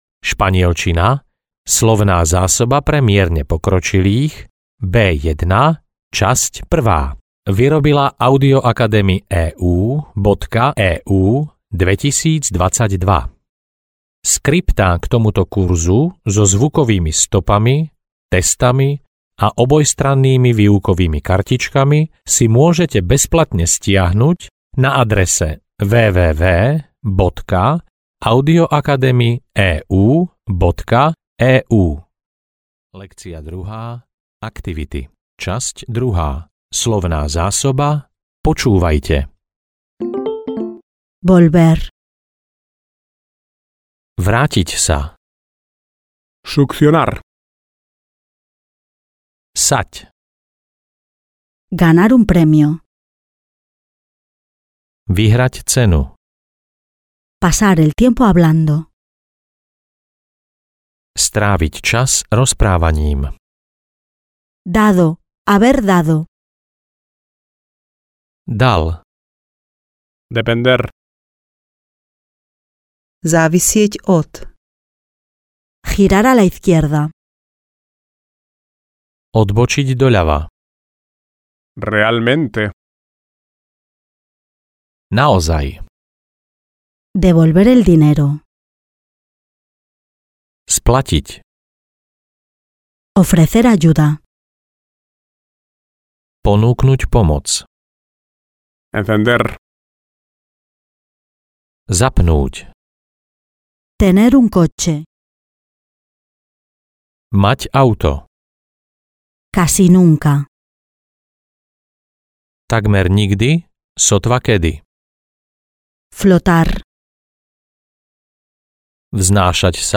Španielčina pre mierne pokročilých B1 – časť 1 audiokniha
Ukázka z knihy